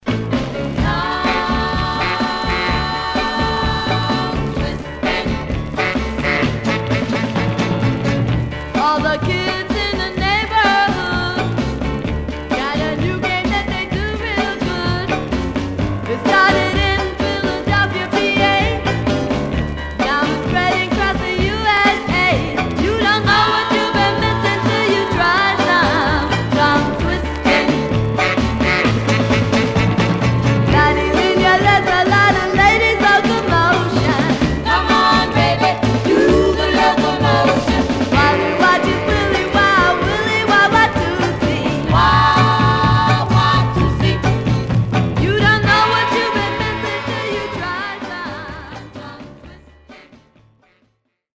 Condition Some surface noise/wear Stereo/mono Mono
White Teen Girl Groups